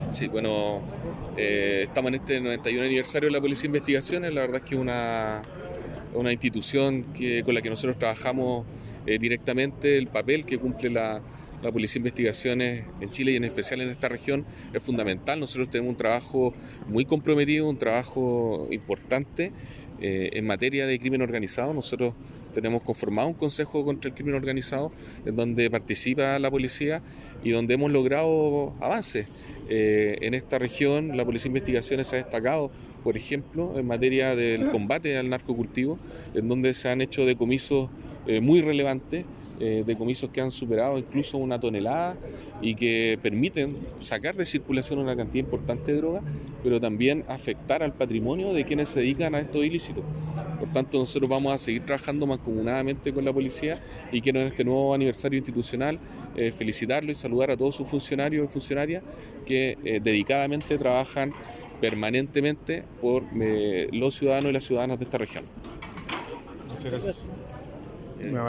“Estamos en el inicio de los 91 años de la PDI para saludar a todos sus funcionarios y funcionarias, con quienes trabajamos directamente. El papel que cumplen en Chile y esta región es fundamental, es un trabajo muy comprometido, importante, nosotros tenemos un Consejo Contra el Crimen Organizado, y hemos logrado avances.Por ejemplo, en materia de investigaciones relacionadas con los ‘narco cultivos’, sacando de circulación importantes cantidades de droga y afectando el patrimonio de quienes se dedican a estos ilícitos”, señaló al término de la ceremonia, Galo Luna, Delegado Presidencial de la región de Coquimbo.